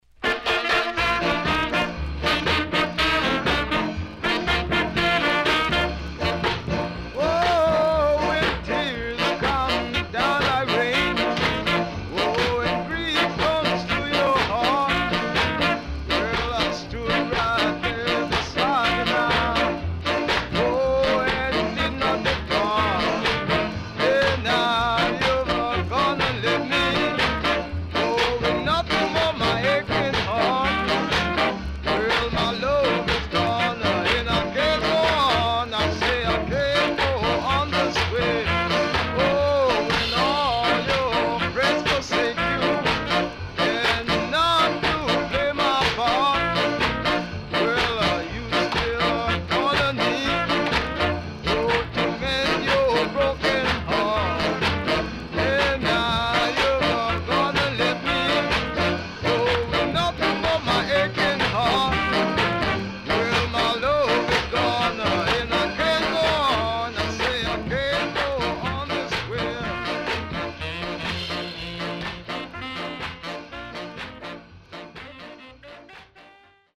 Killer Ska Inst
SIDE A:全体的にチリノイズ入ります。